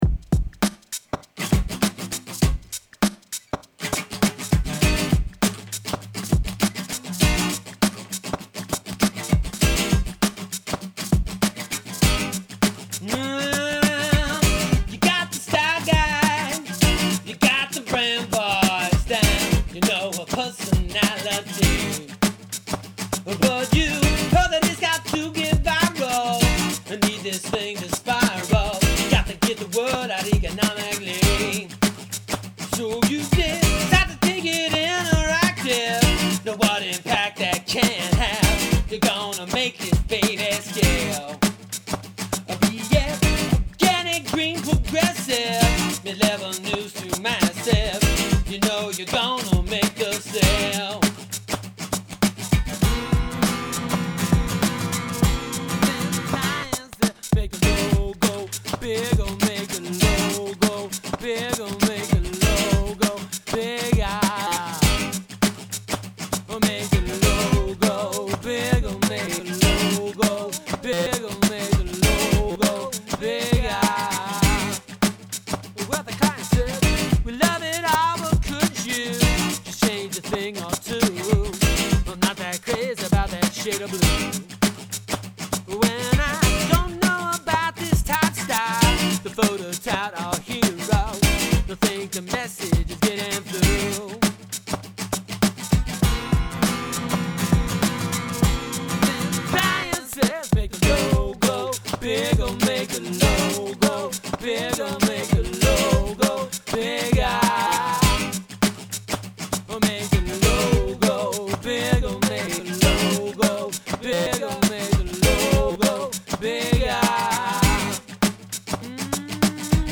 Messed up sketch recording (M-Audio FireWire 410 was fritzing out) of a song I threw together for Stream 2008 but decided not to perform.